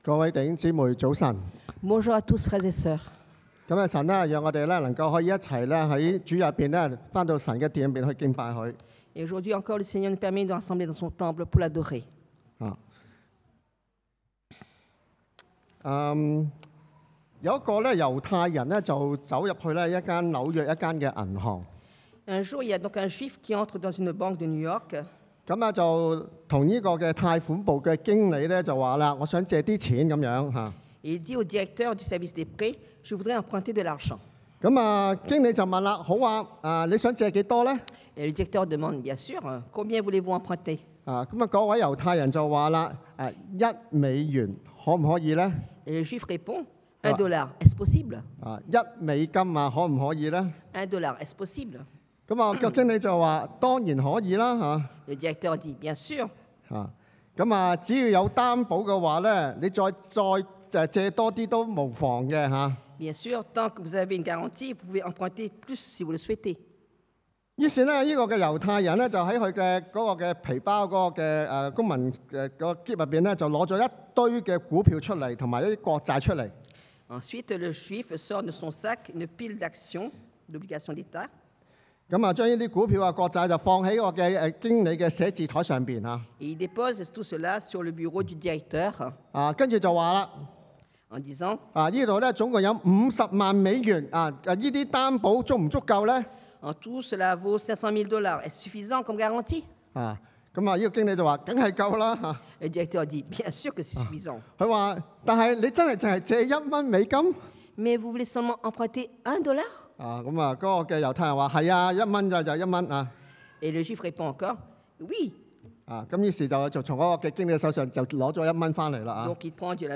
Rester ferme 堅挺站立 – Culte du dimanche
1 Rois 列王紀上 19:15-21 Type De Service: Predication du dimanche « Etre juste